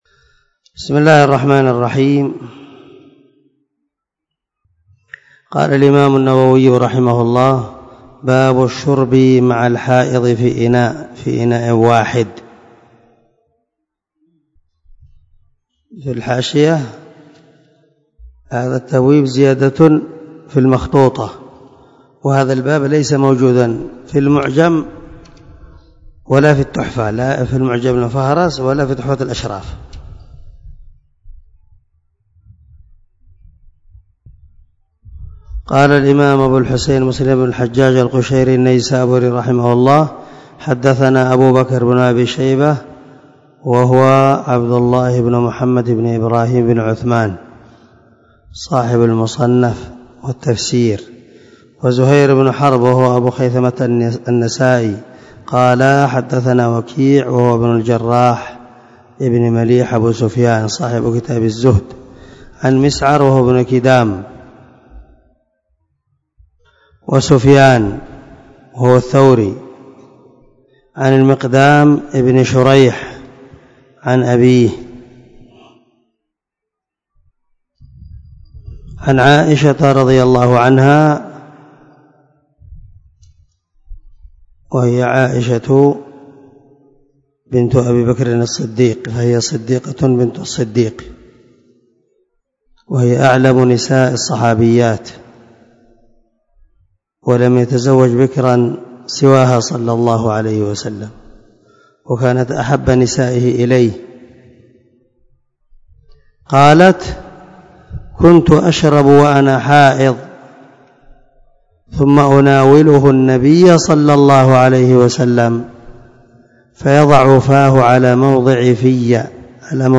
221الدرس 5 من شرح كتاب الحيض حديث رقم ( 300 - 301 ) من صحيح مسلم